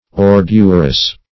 Ordurous \Or"dur*ous\